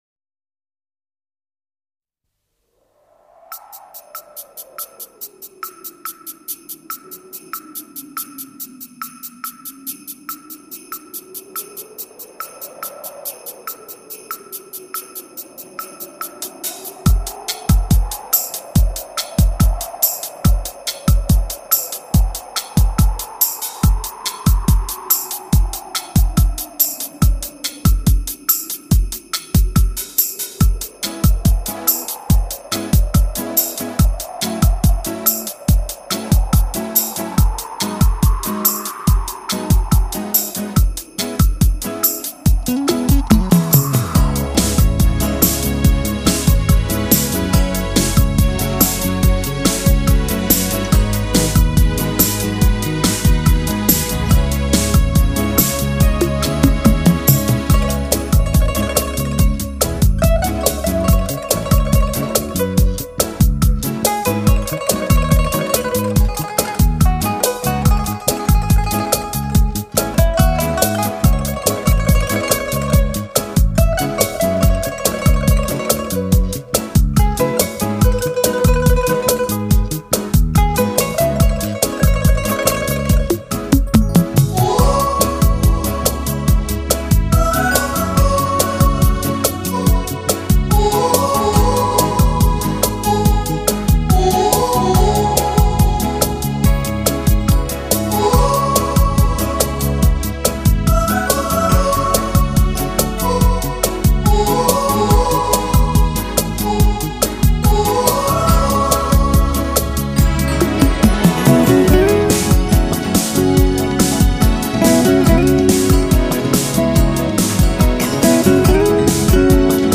音响发烧友协会测音指定曲目，一站收录高品质音效各类题材
发烧界年度评选最热360度立体环绕音，一次给你耳朵